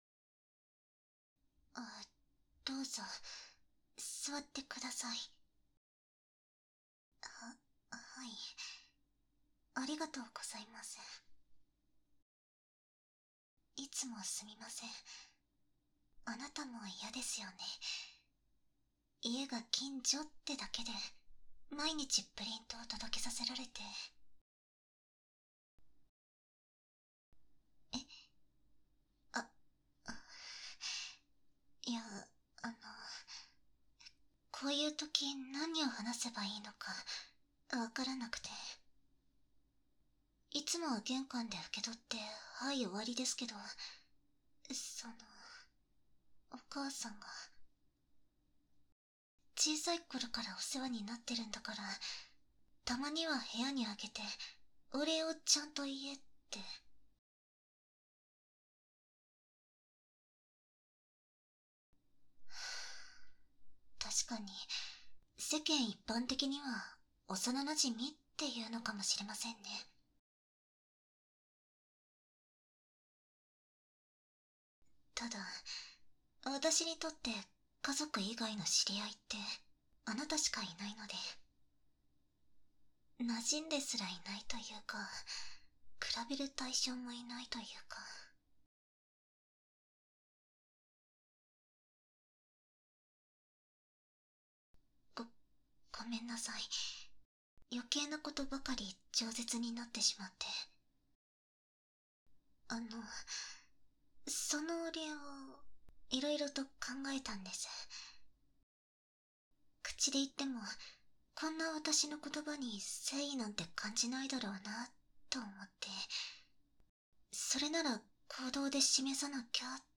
掏耳 环绕音